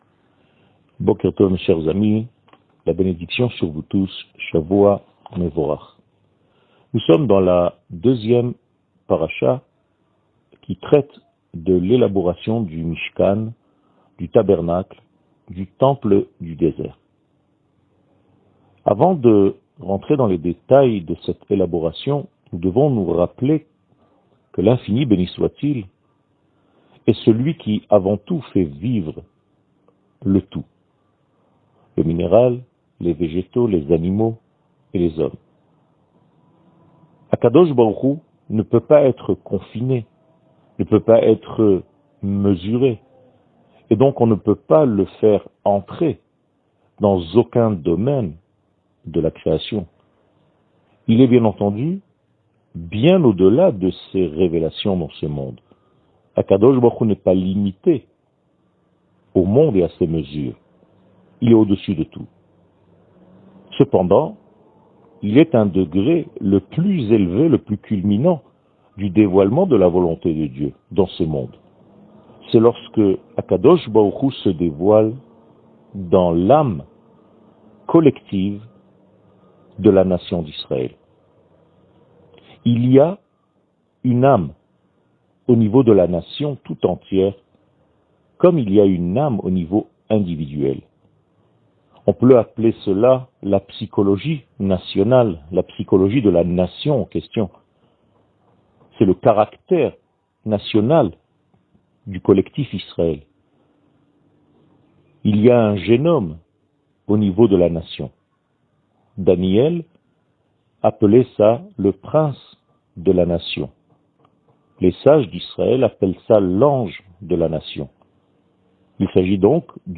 שיעור מ 06 פברואר 2022